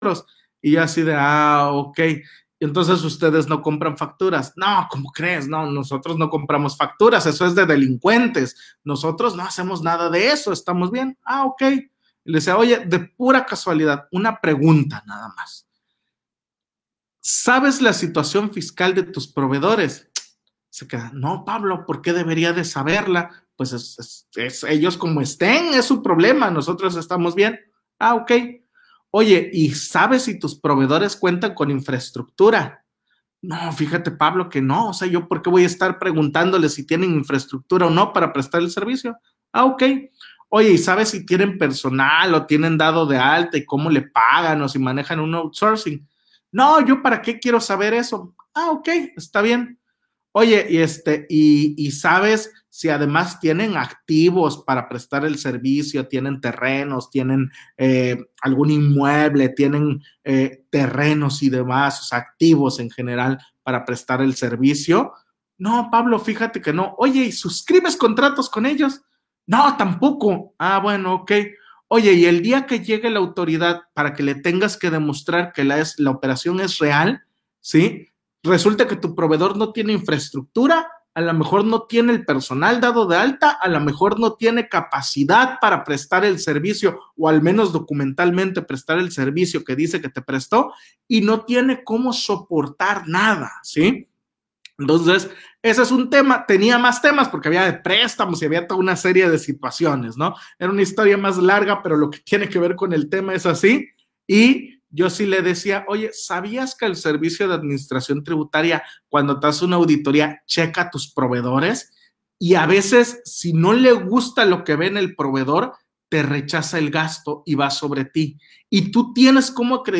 VideoConferencia Operaciones Inexistentes y su defensa efectiva